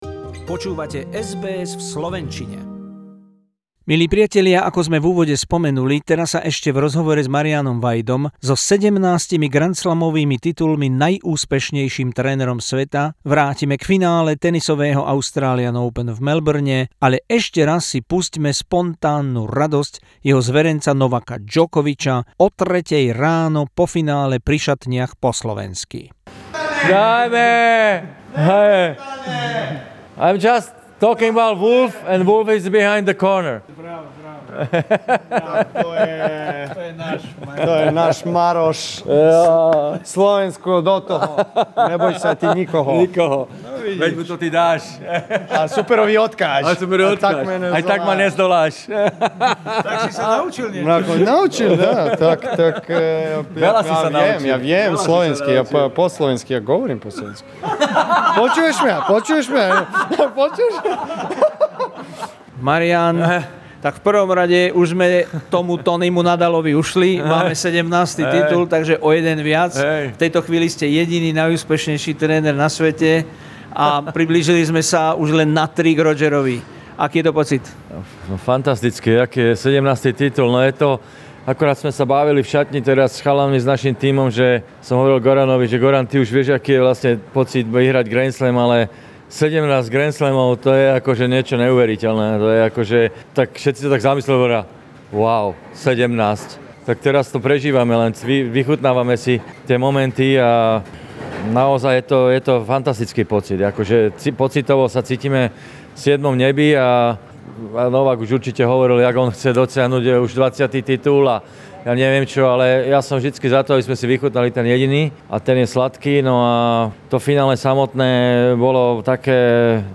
Interview with the most successful tennis coach in the history Marian Vajda after Novak Djokovic won 17th Grand Slam title beating Dominic Thiem in the Australian Open 2020 final. Conducted after 2am in the morning in Rod Laver Arena, at the end Djokovic speaking Slovak language.